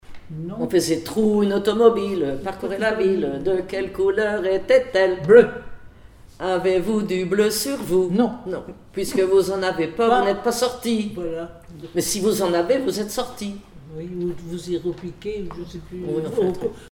formulette enfantine : amusette
Comptines et formulettes enfantines